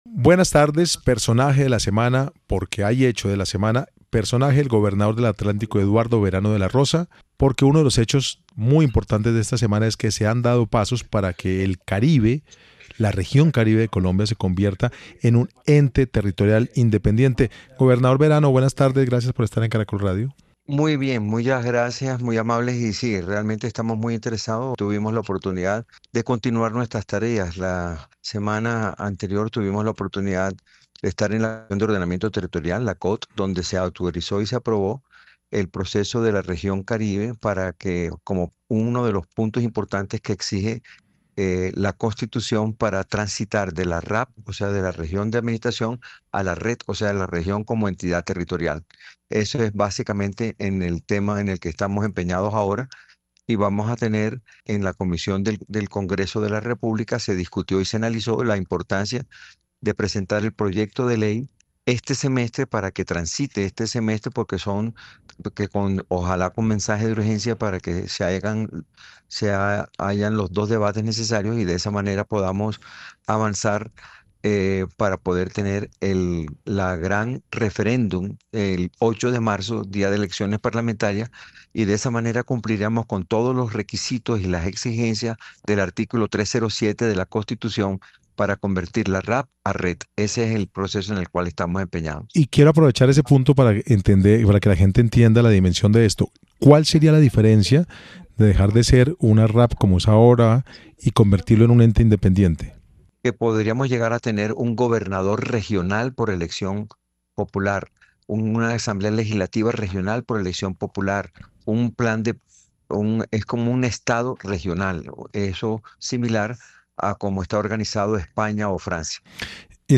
“Ese es el cronograma. Hoy se corroboró ese esquema”, afirmó durante su entrevista en Caracol Radio.